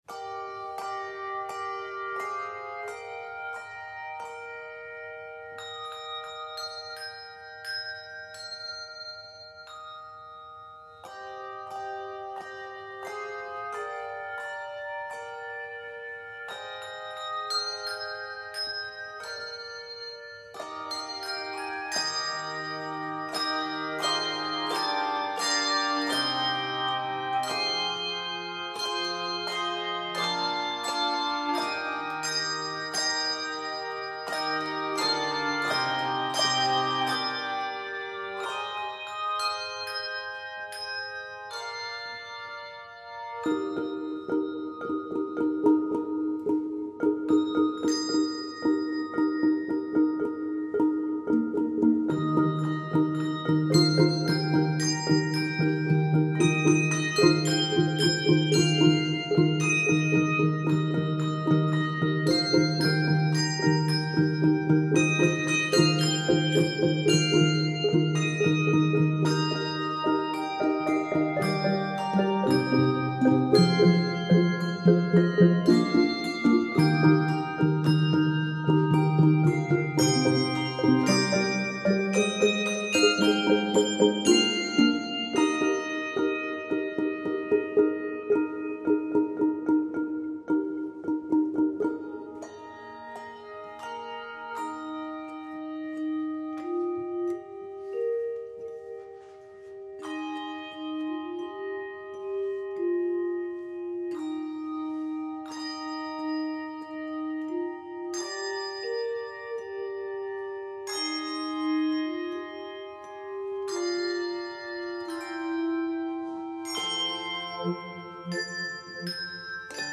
lively arrangement of the well-known hymn
Keys of Eb major and F major.